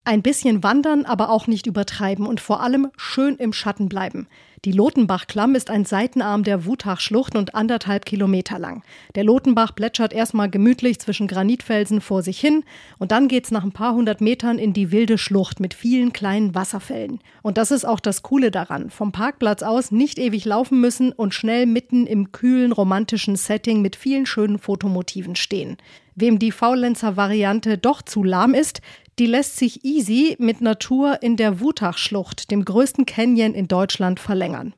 Nachrichten „Schön im Schatten bleiben“